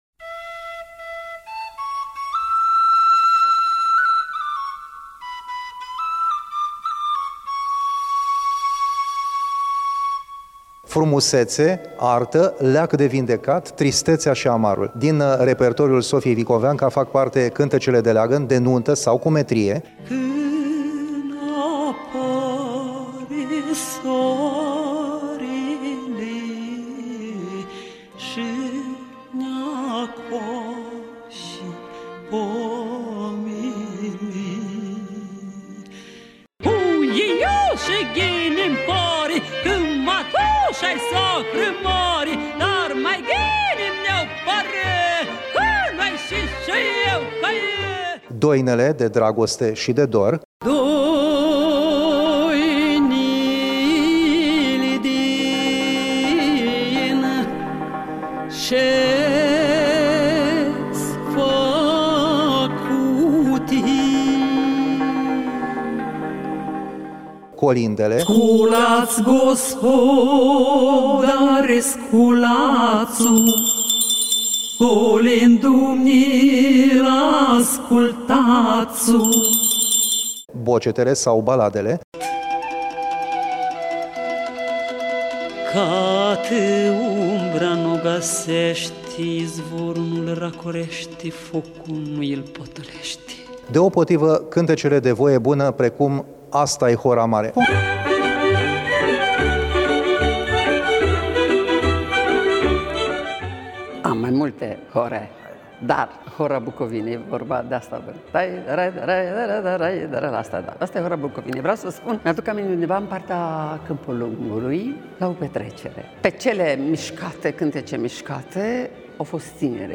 Interviu-cu-Sofia-Vicoveanca.mp3